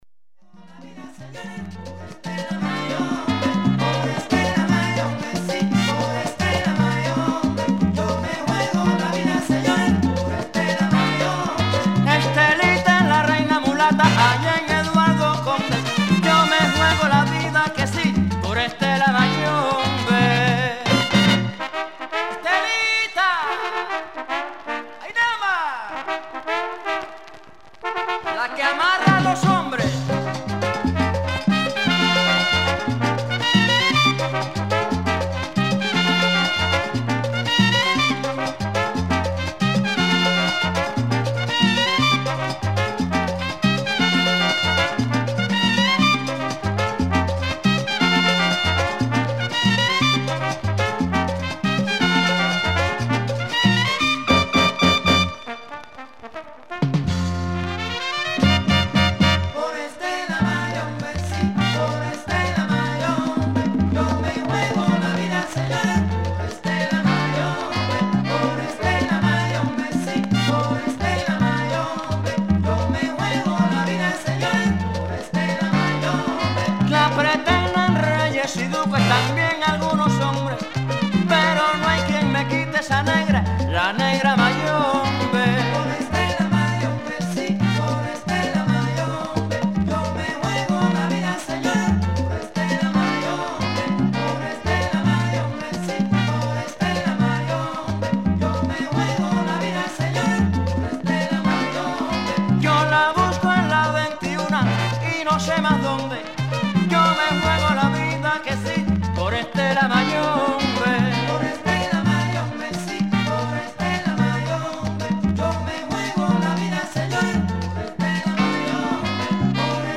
キューバのGuaracha , Son系の女性ボーカル